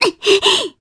Reina-Vox_Damage_jp_02.wav